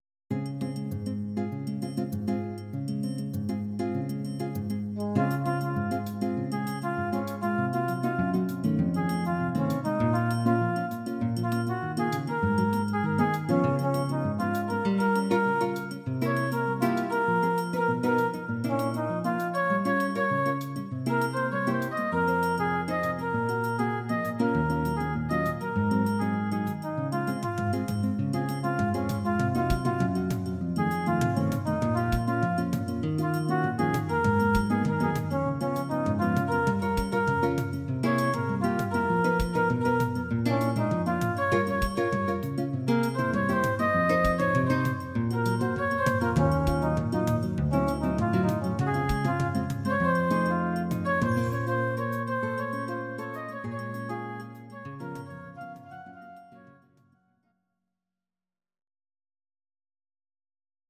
Audio Recordings based on Midi-files
Ital/French/Span